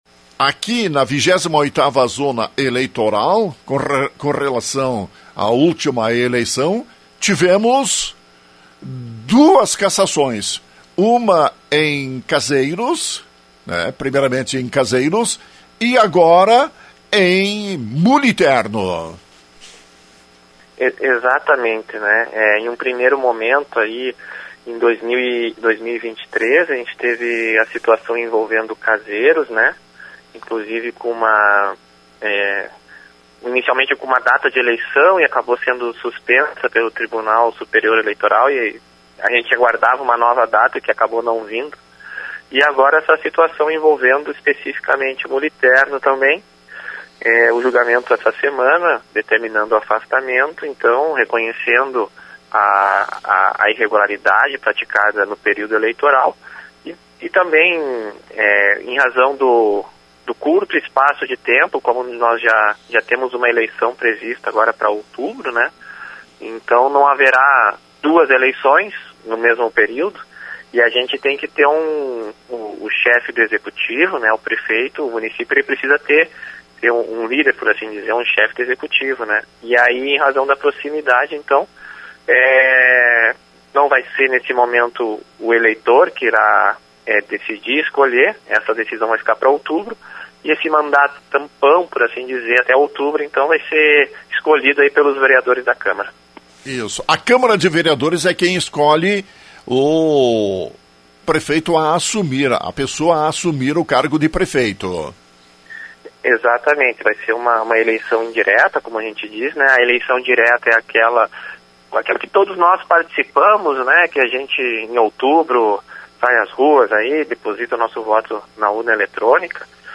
Na manhã desta sexta-feira, Rádio Lagoa FM entrevistou promotor de Justiça Eleitoral, Felipe Lisboa Barcelos, que atuou no processo.